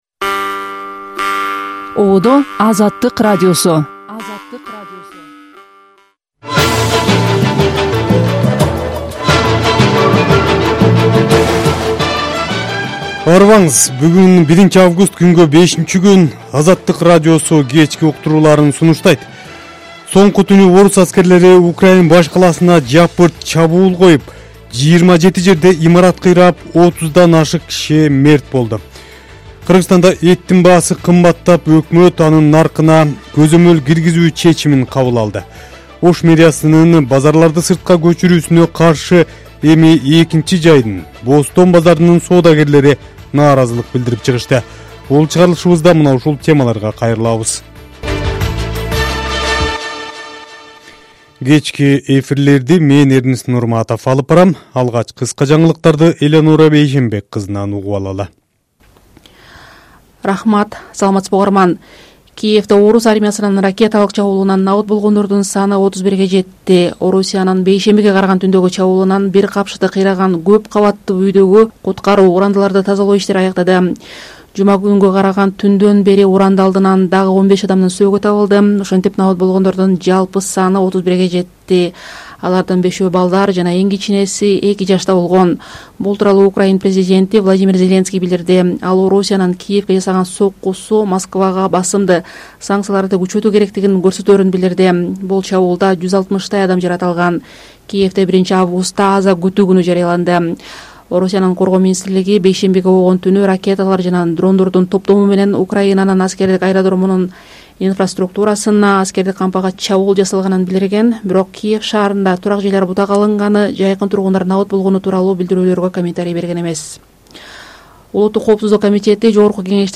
Азаттык: Кечки радио эфир | 01.08.2025 | Жогорку Кеңештин экс-депутаттары көмүр кенин басып алууга шек саналып камалды